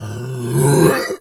pgs/Assets/Audio/Animal_Impersonations/bear_pain_hurt_groan_06.wav at master
bear_pain_hurt_groan_06.wav